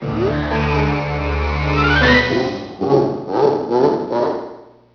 sm64_bowser_message.wav